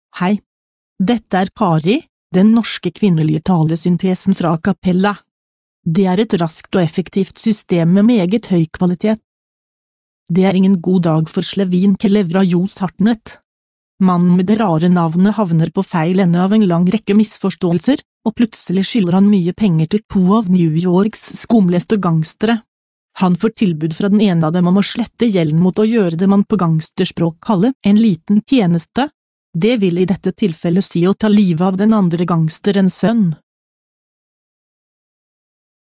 Texte de d�monstration
Acapela High Quality Text To Speech Voices; distribu� sur le site de Nextup Technology; femme; norv�gien